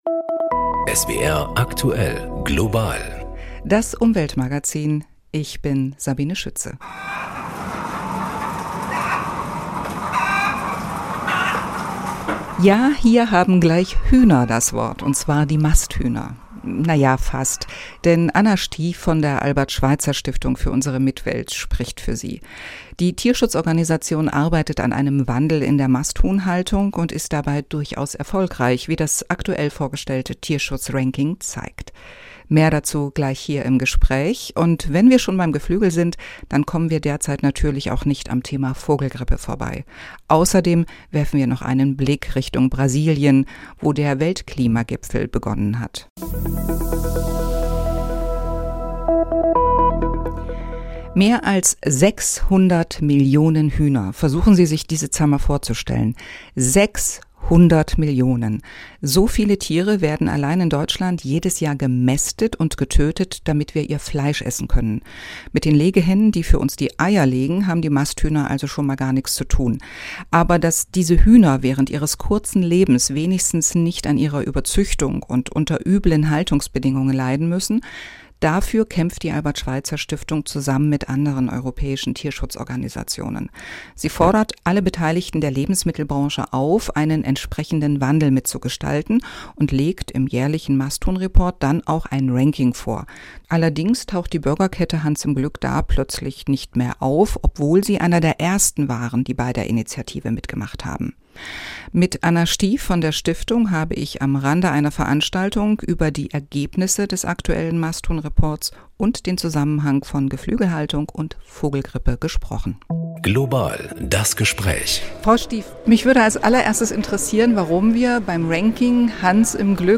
Außerdem geht es um mögliche Impfungen gegen die Vogelgrippe, Fischfangquoten in der Ostsee und natürlich den Weltklimagipfel in Brasilien. Eine Sendung